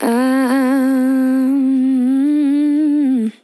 Categories: Vocals Tags: Ahhhmmm, dry, english, female, fill, LOFI VIBES, sample